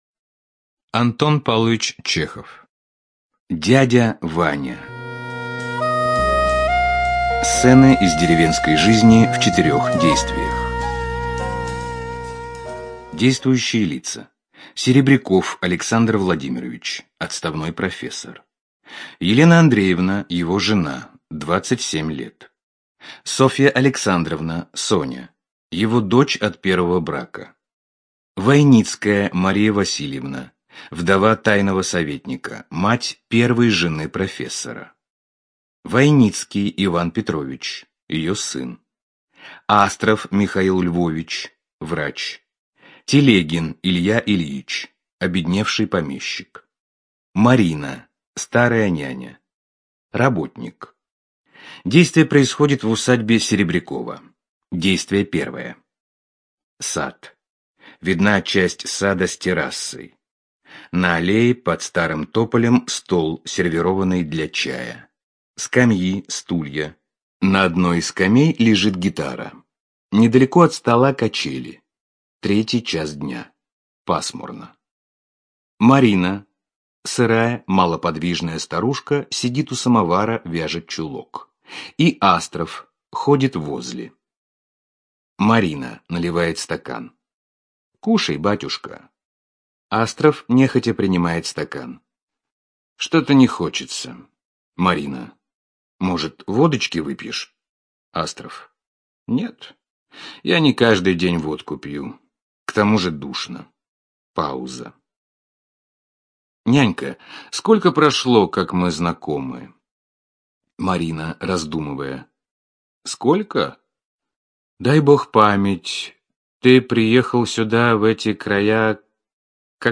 ЖанрДраматургия